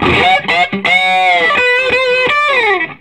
Index of /90_sSampleCDs/USB Soundscan vol.22 - Vintage Blues Guitar [AKAI] 1CD/Partition C/13-SOLO B060